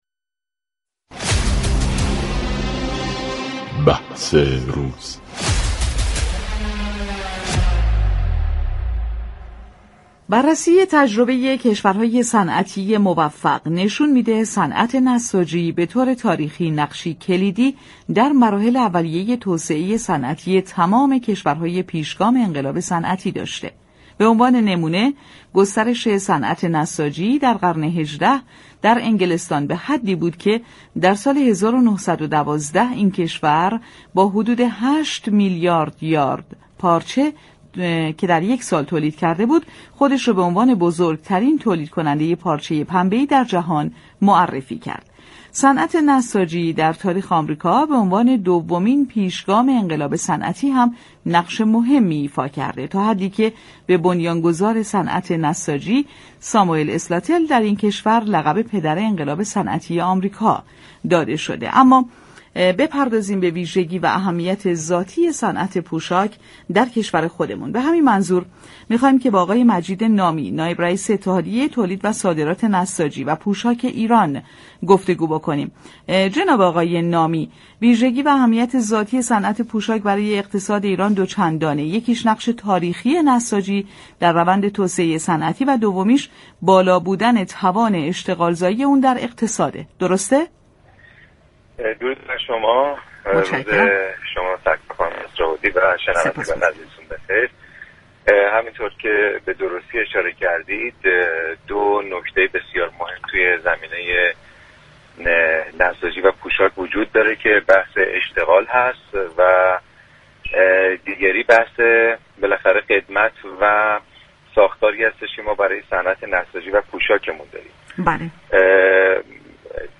در گفتگو با برنامه بازار تهران رادیو تهران